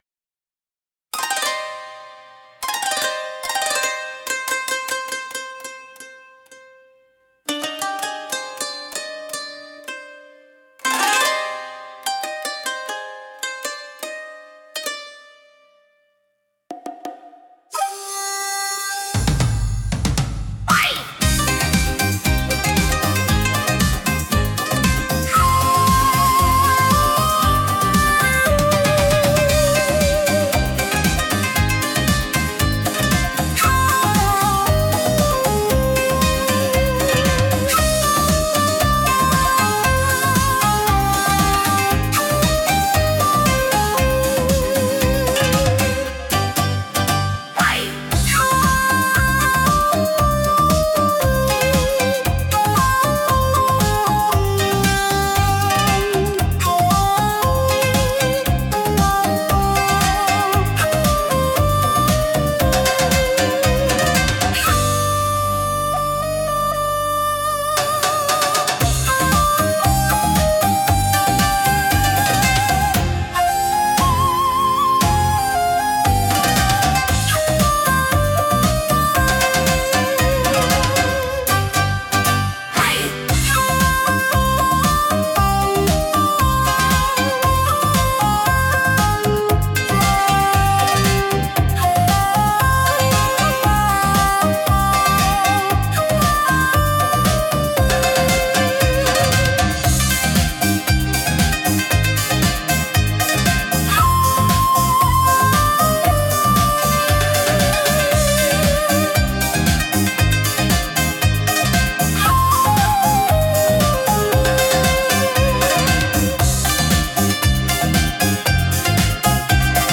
聴く人に日本独特の風情と心の安らぎを届けるジャンルです。